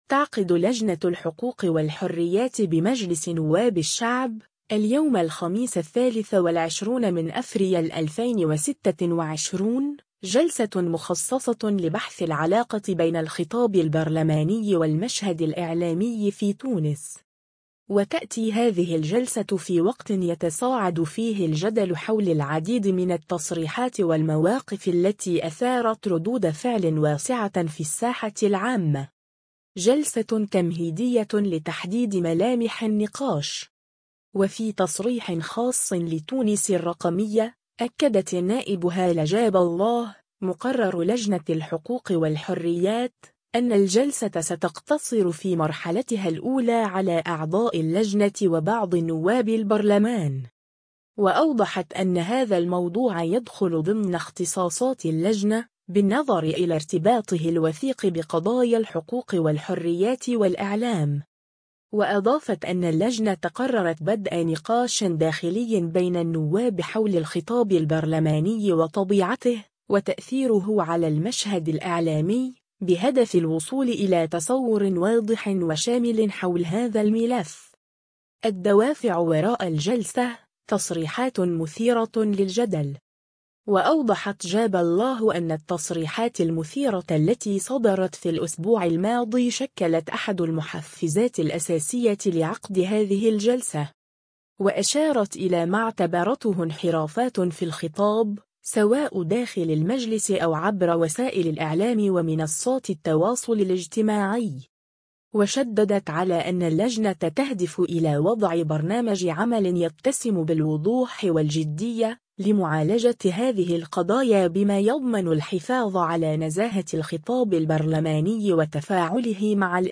وفي تصريح خاص لـ”تونس الرقمية”، أكدت النائب هالة جاب الله، مقرر لجنة الحقوق والحريات، أن الجلسة ستقتصر في مرحلتها الأولى على أعضاء اللجنة وبعض نواب البرلمان.